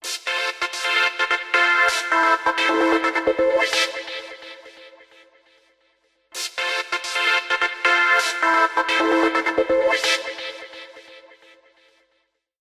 P O L I C E